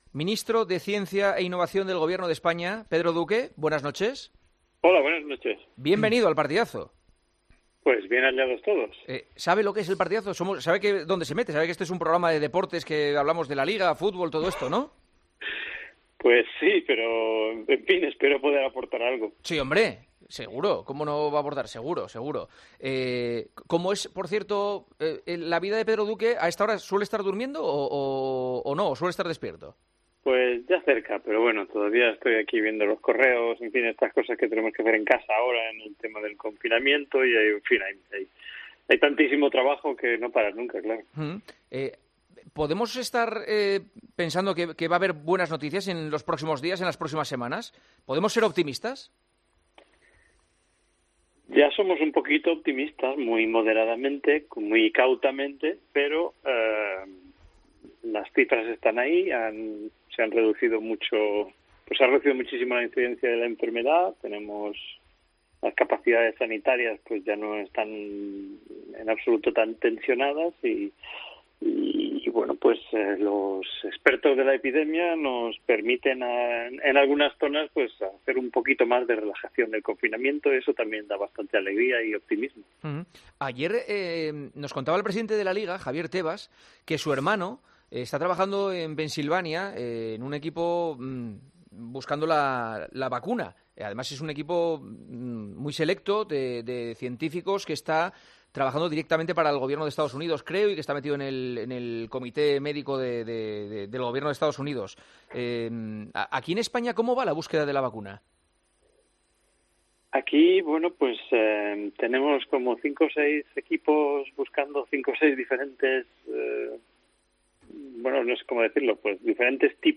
AUDIO - ENTREVISTA A PEDRO DUQUE, MINISTRO DE CIENCIA E INNOVACIÓN, EN EL PARTIDAZO DE COPE